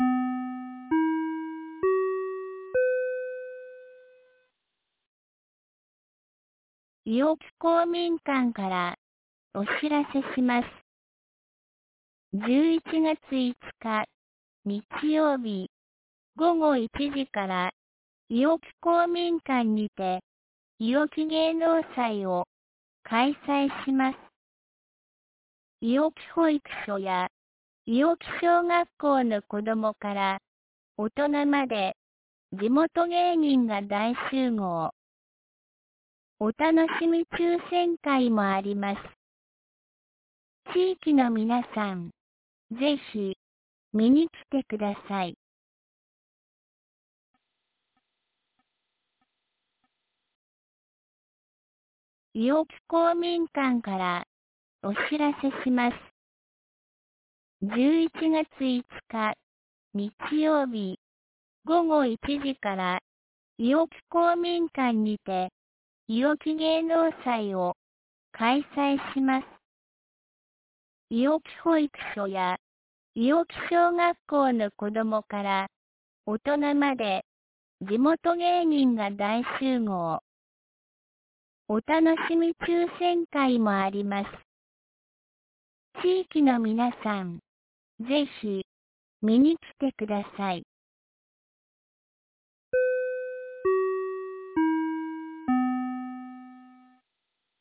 2023年11月04日 17時11分に、安芸市より伊尾木、下山へ放送がありました。